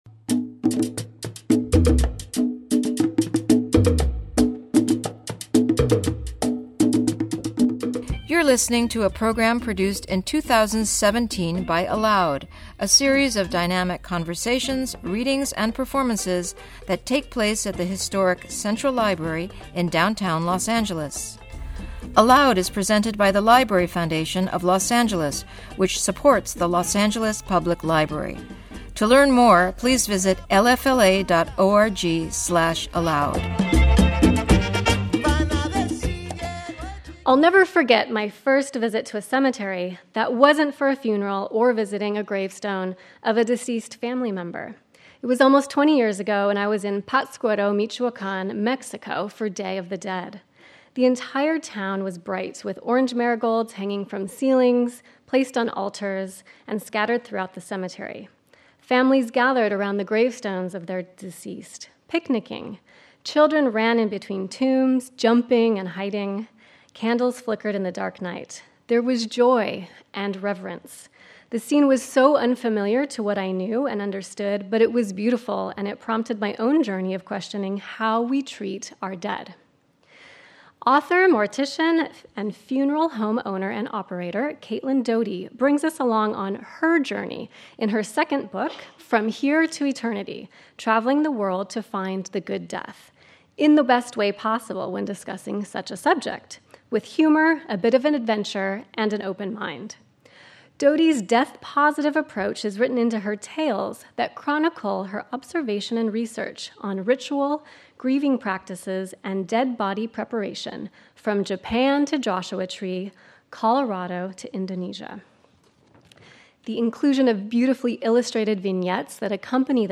Caitlin Doughty In Conversation